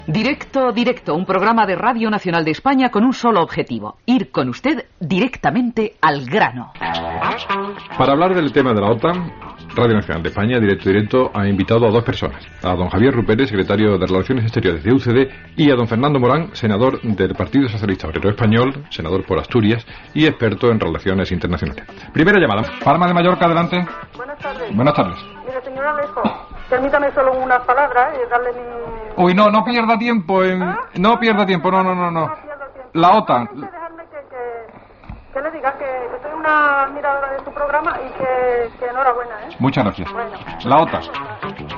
Identificació del programa, espai dedicat al possible ingrés d'Espanya a l'OTAN. Noms dels polítics invitats i trucada des de Palma per opinar.